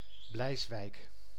Bleiswijk (Dutch: [ˈblɛisʋɛik]
Nl-Bleiswijk.ogg.mp3